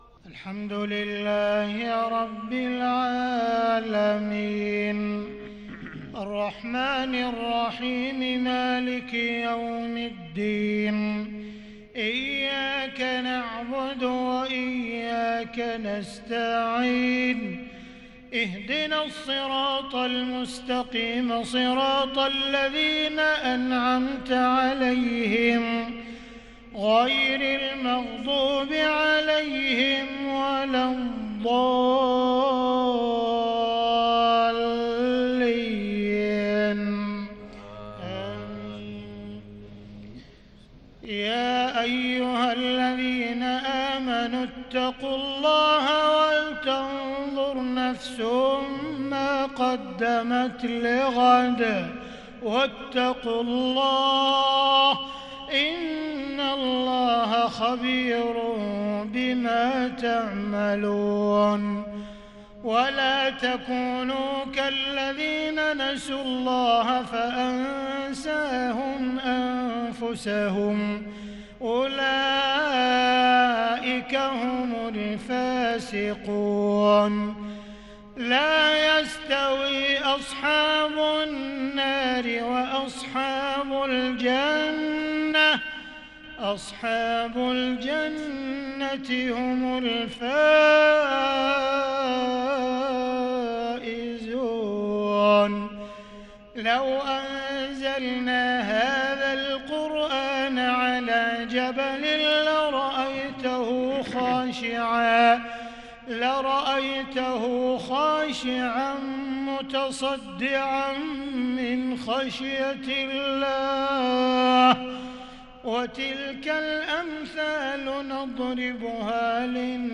عشاء الإثنين 9-8-1442هـ من سورة الحشر | Isha prayer from Surat AlHashr 22/3/2021 > 1442 🕋 > الفروض - تلاوات الحرمين